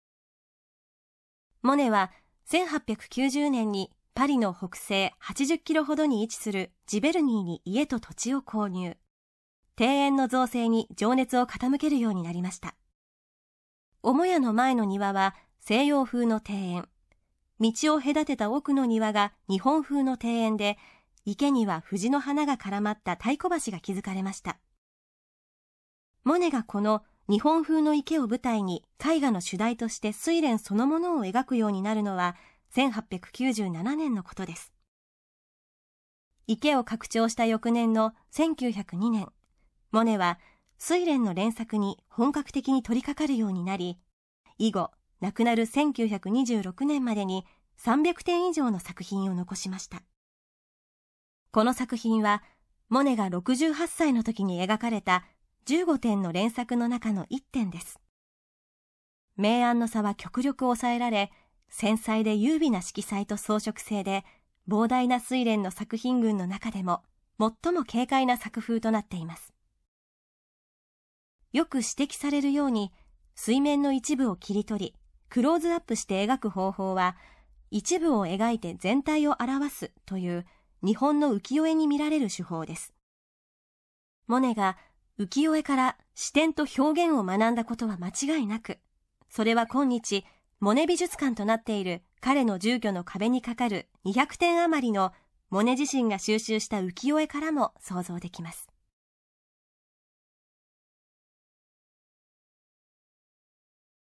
作品詳細の音声ガイドは、すべて東京富士美術館の公式ナビゲーターである、本名陽子さんに勤めていただいております。